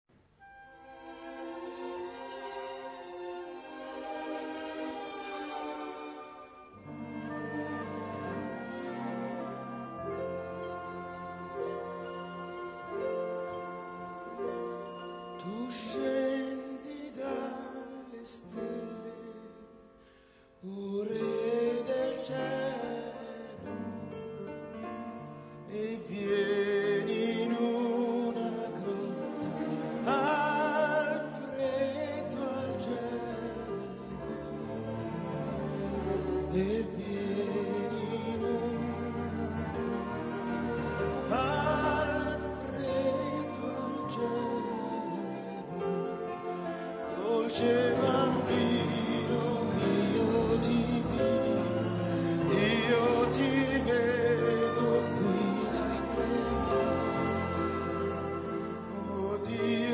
key: F-major